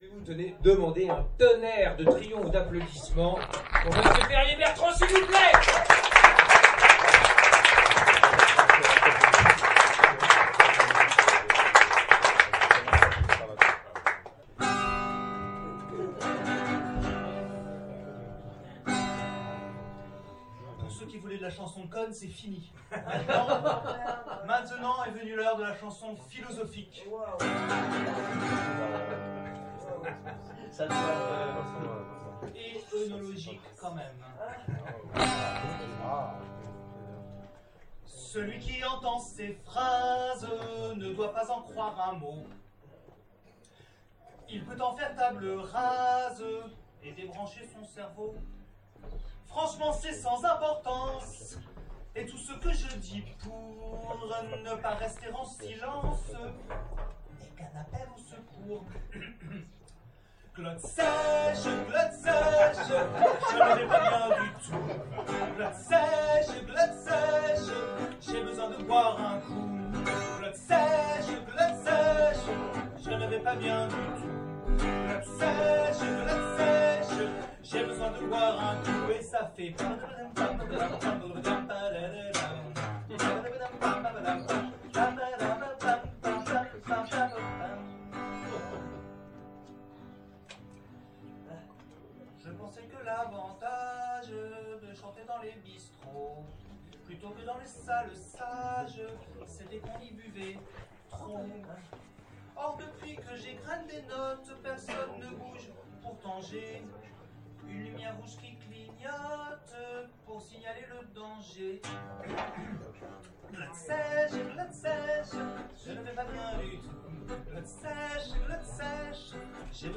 Stand-uppers, comédiens, improvisateurs et chansonneurs remplirent promptement la salle du sous-sol pour applaudir les autres et chercher quelques brava pour soi-même parce que charité bien ordonnée, blablabla. Implacable, j’y infiltrai des éléments-clefs de mon programme politique contre la Glotte sèche.